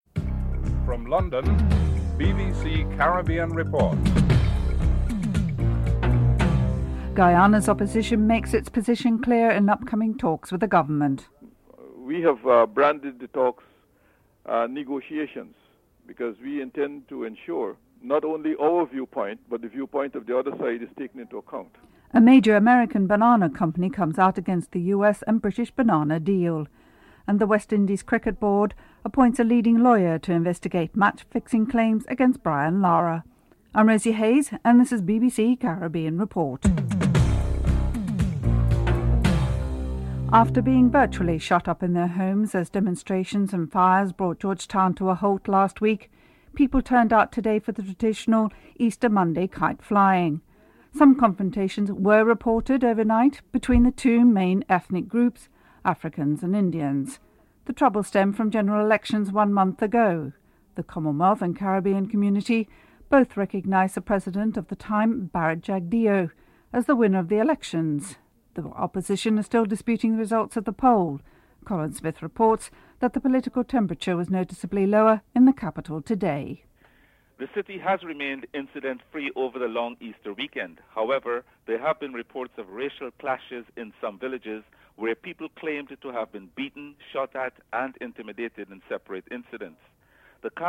1. Headlines (00:00-00:39)
4. Dutch Parliament is continuing to debate the future of young Dutch Antilleans wanting to enter the Netherlands permanently. Paul Rosenmoller of the Opposition GreenLeft Party in the Netherlands is interviewed (08:52-11:56)